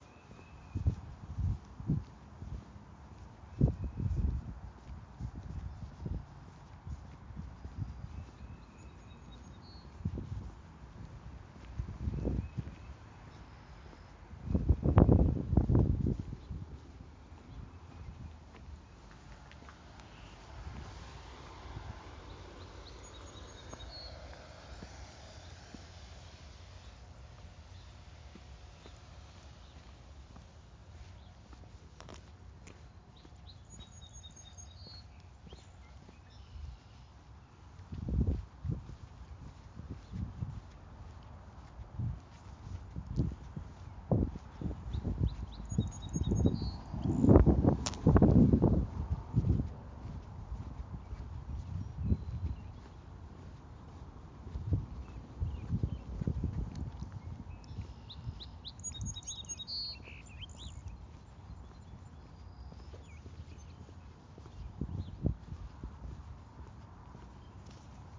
危机现场录音 " Crujido Papel
描述：纸张噪音的现场记录
Tag: 噪声场 录音